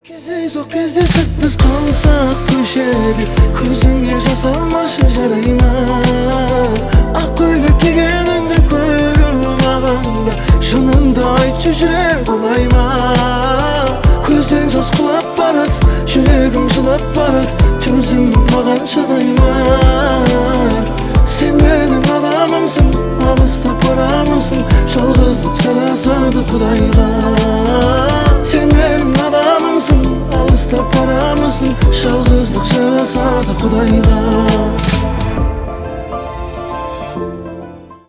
саундтрек
поп